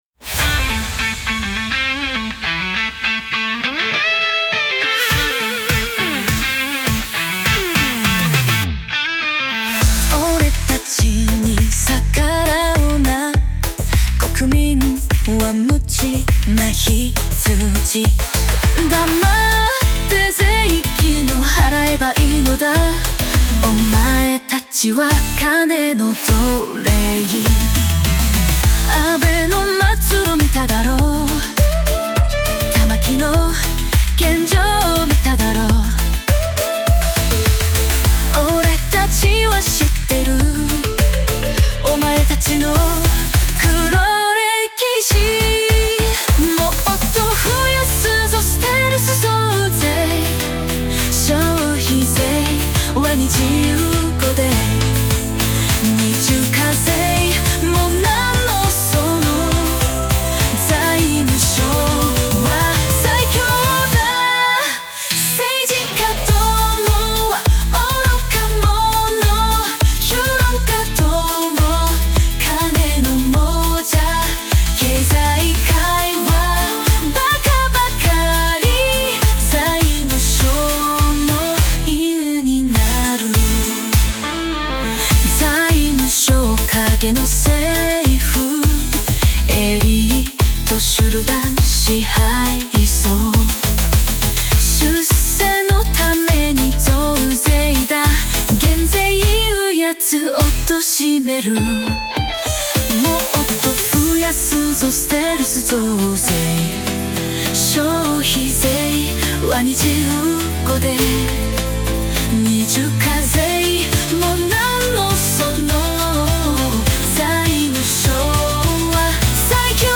四日市市文化会館第一ホール